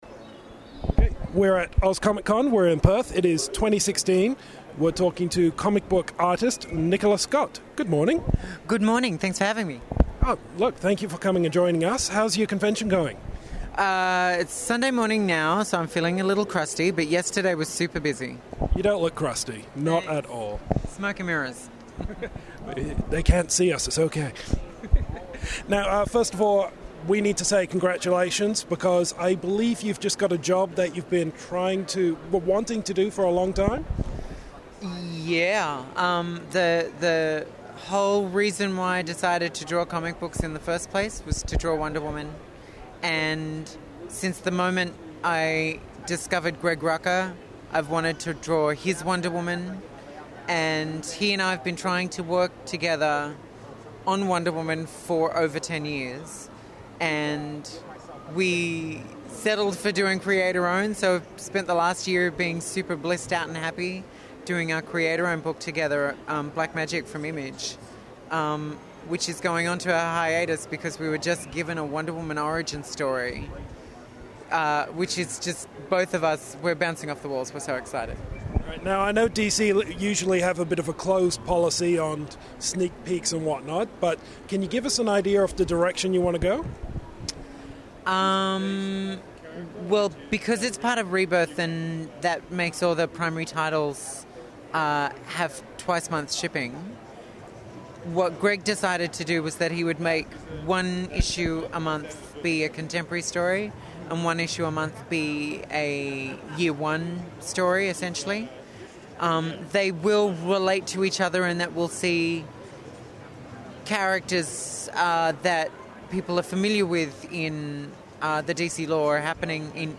I hope you didn't think we were done with our Oz Comic-Con coverage!